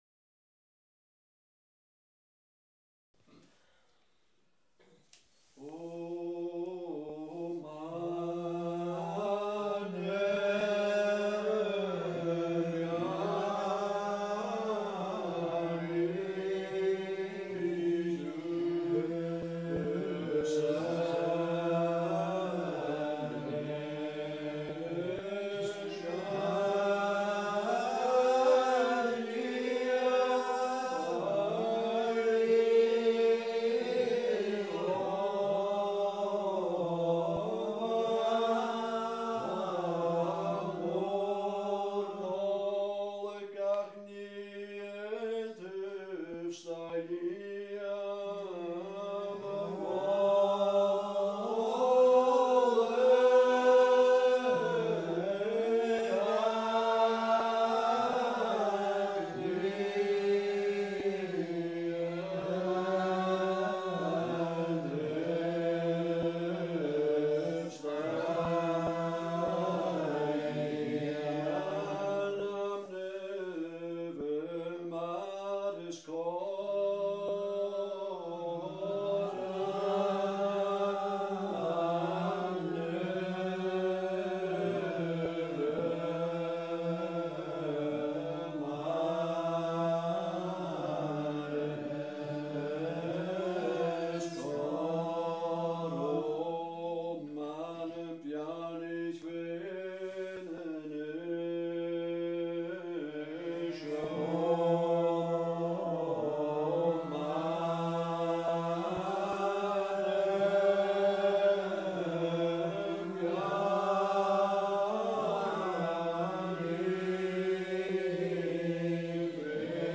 Gaelic Psalmody - class 4
A’ Seinn nan Sailm Gaelic Psalmody